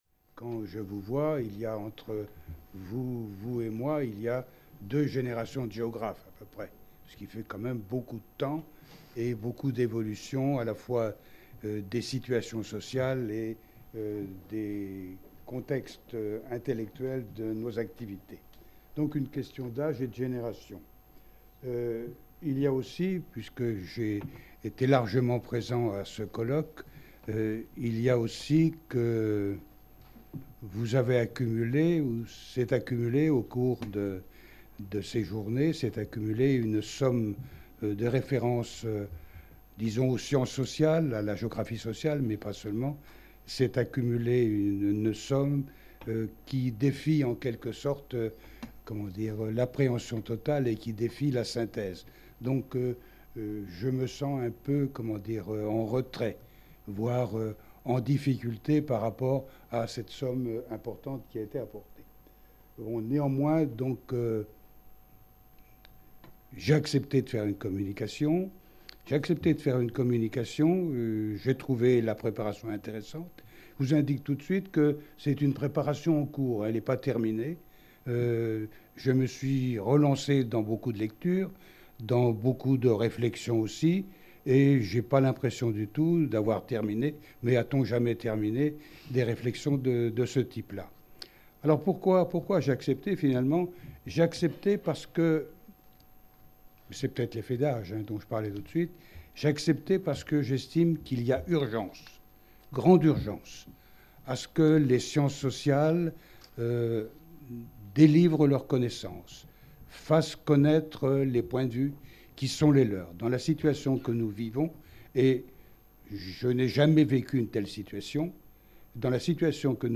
Cette communication a été donnée en conclusion du colloque Approches critiques de la dimension spatiale des rapports sociaux qui s'est déroulé à Caen du 26 au 28 juin 2019. Le point de départ de ce colloque est la dynamique et la visibilité récentes des approches critiques des rapports sociaux dans la géographie française, alors que les analyses mettant l’accent sur les inégalités et les rapports de pouvoir et de domination sont incontournables depuis longtemps en géographie anglophone comme bien entendu en sociologie.